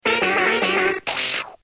Cri de Soporifik dans Pokémon X et Y.